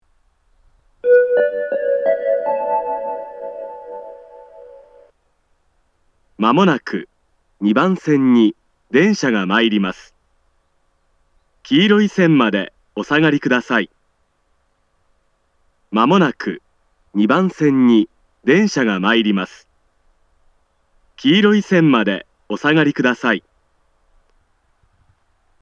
また、スピーカーが上下兼用なので、交換のある列車の場合、放送が被りやすいです。
仙石型（男性）
接近放送